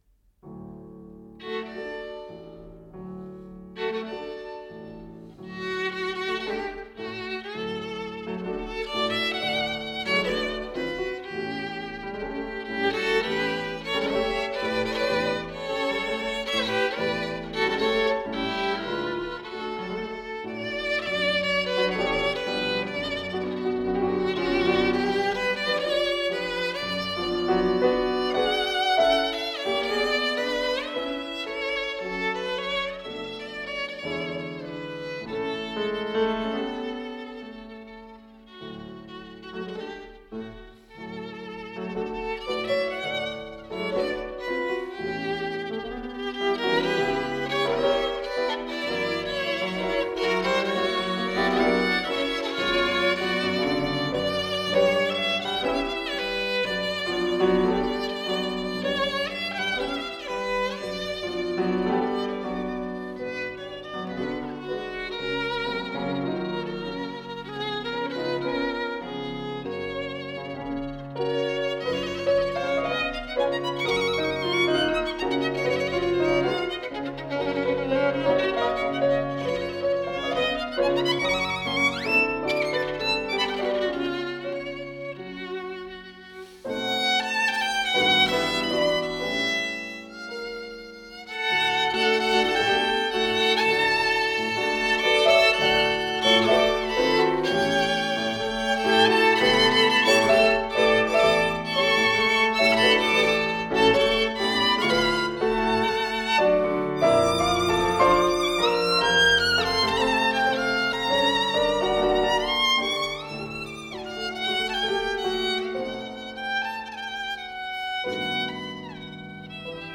使用了五把17、18世纪出产的名琴，音色高贵娇艳，独具美质。
一听这提琴的声音就知道是古货,醇厚啊曲子实在是太熟悉了,没得说,经典就是了。